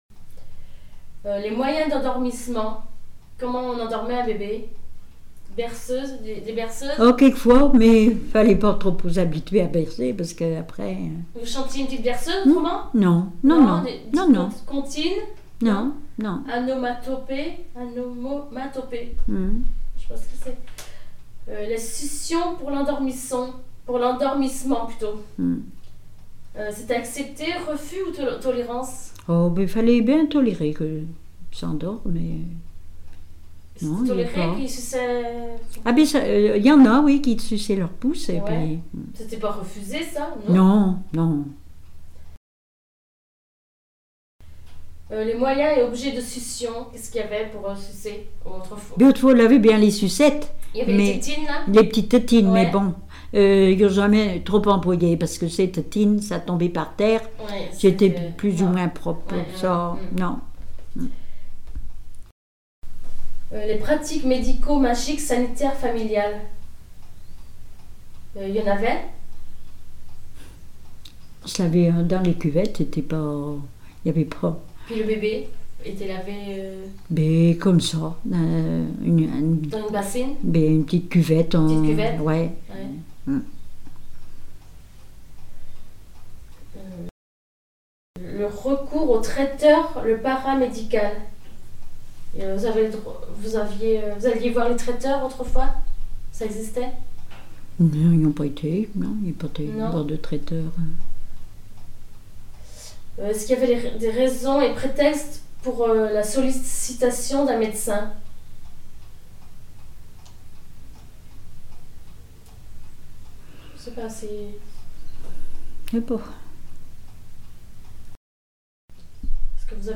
Témoignages sur la vie domestique
Catégorie Témoignage